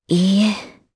Priscilla-Vox-Deny_jp.wav